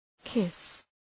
Προφορά
{kıs}